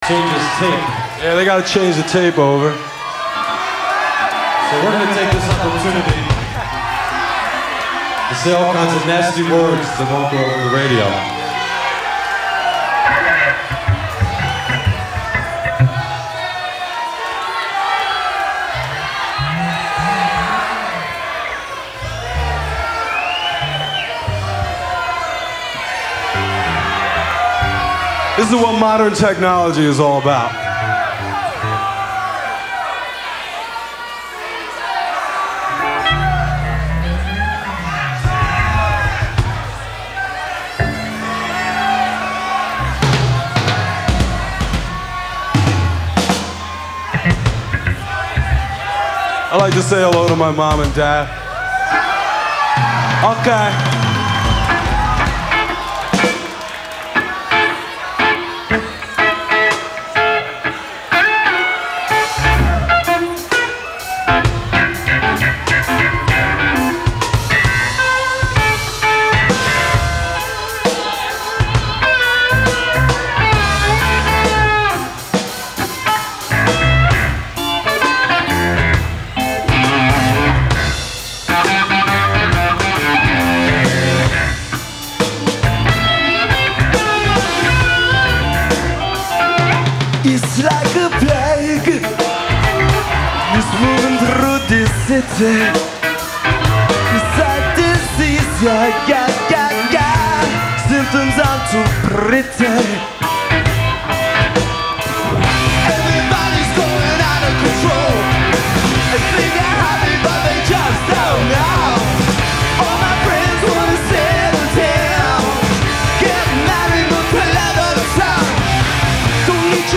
lead vocals
guitar
keyboards
bass